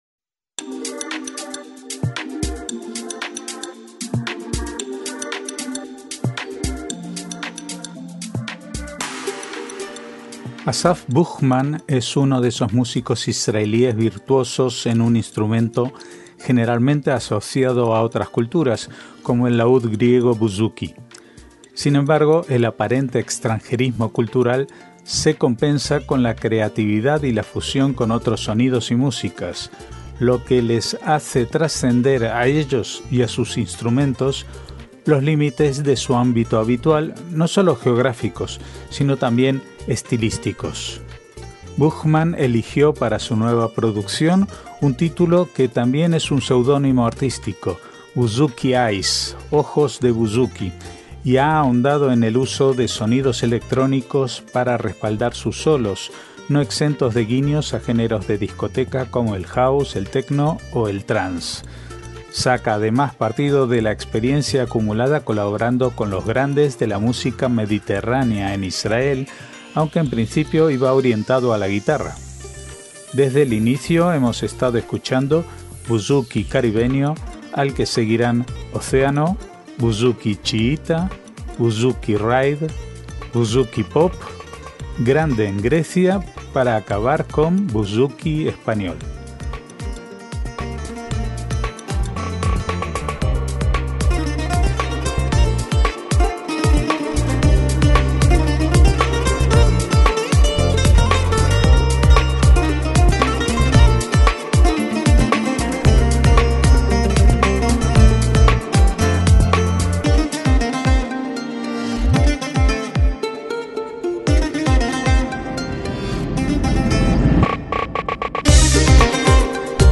MÚSICA ISRAELÍ